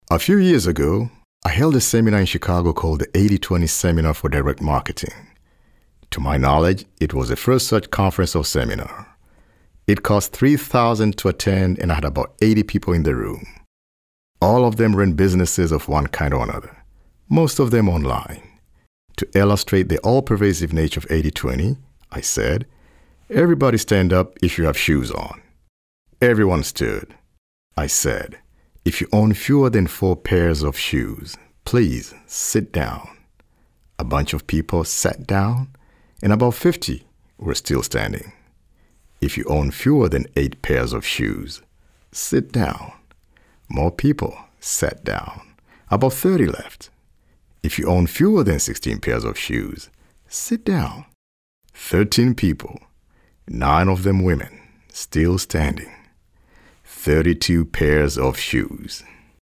Experienced voice over talent with a baritone, calm, easy listening, "the guy next door" voice.
Sprechprobe: Industrie (Muttersprache):